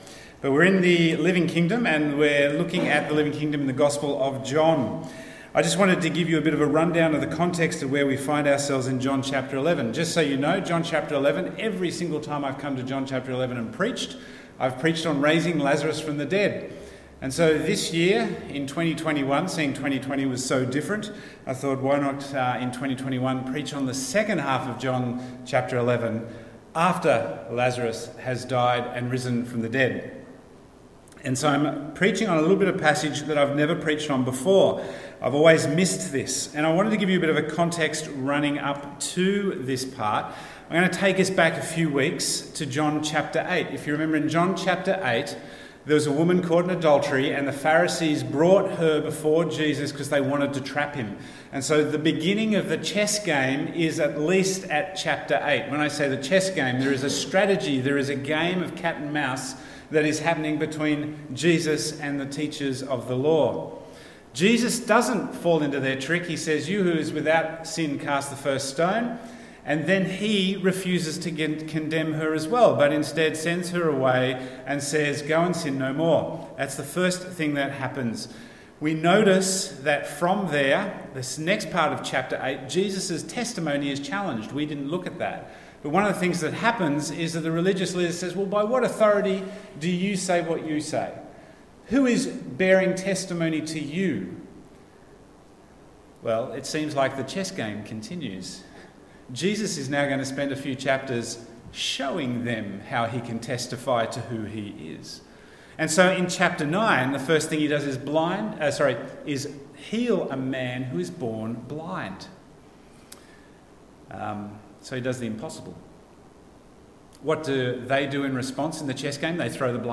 Bible Text: John 11:45-57 | Preacher